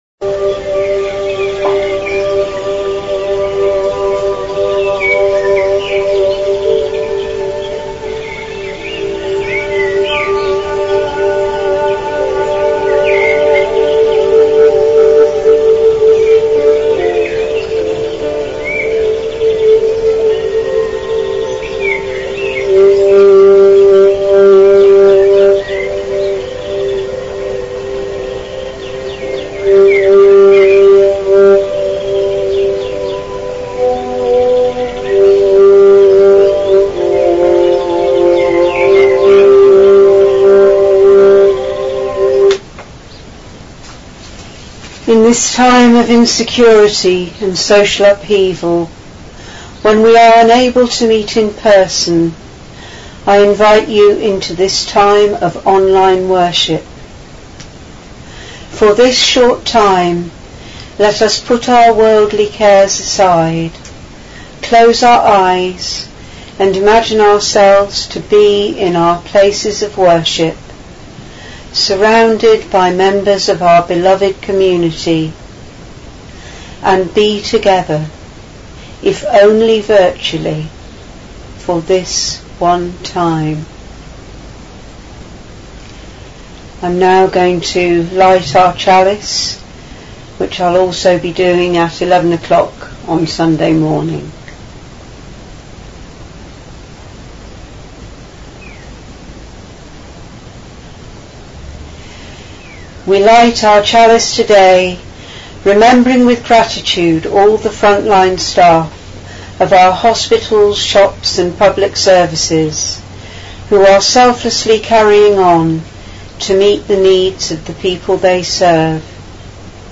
I will start by playing some gentle music to bring us together in this virtual time of worship…